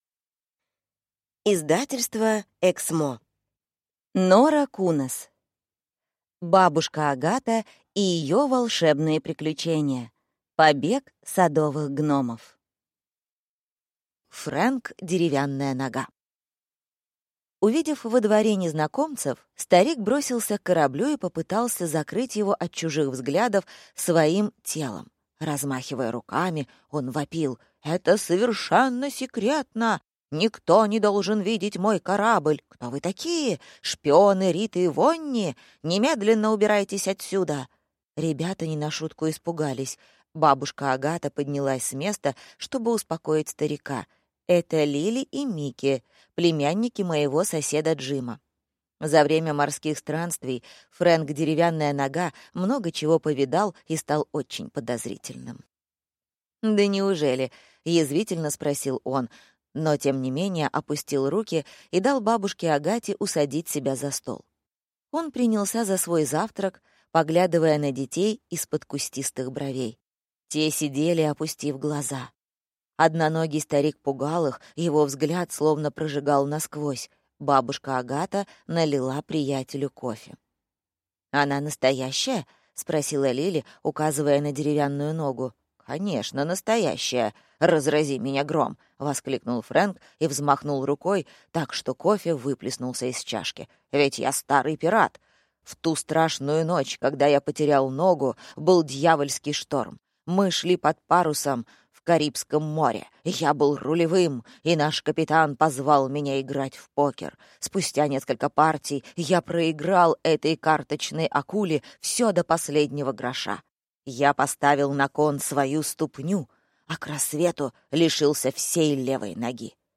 Аудиокнига Побег садовых гномов | Библиотека аудиокниг